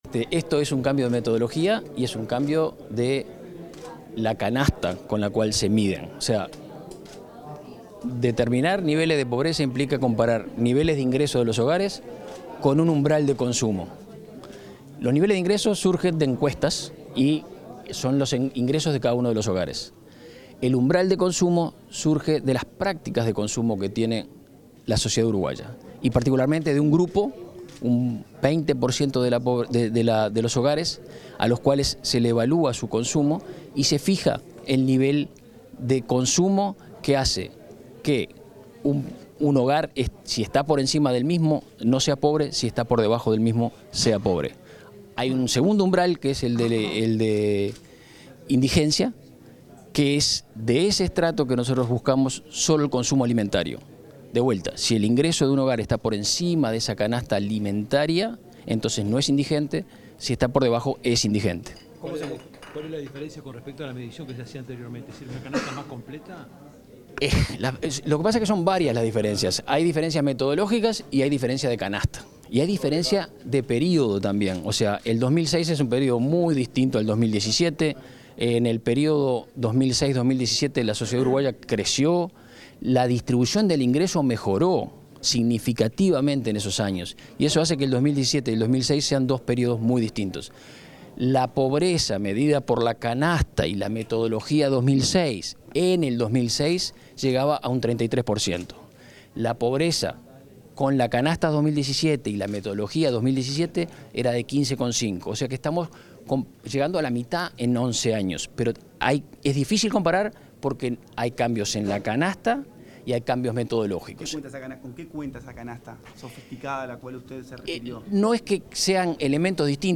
Declaraciones del director del INE, Marcelo Bisogno
El director del Instituto Nacional de Estadística, Marcelo Bisogno, brindó declaraciones a la prensa luego de la presentación de los datos